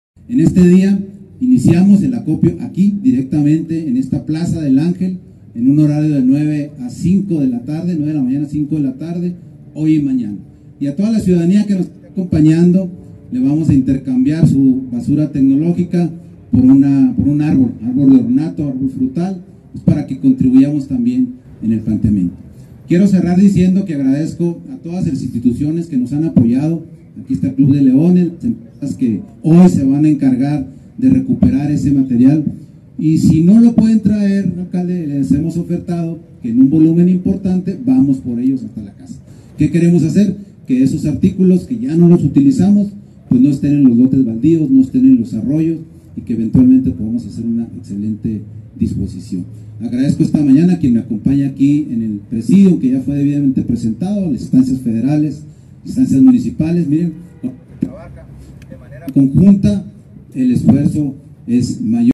AUDIO: GABRIEL VÁLDEZ, SECRETARÍA DE DESARROLLO URBANO Y ECOLOGÍA (SEDUE)
Chihuahua, Chih.- El secretario de Desarrollo Urbano y Ecología (SEDUE), Gabriel Váldez, encabezó la presentación del proyecto «Chihuahua Recicla«, para impulsar a la ciudadanía y a empresas sumarse a acciones de reciclaje.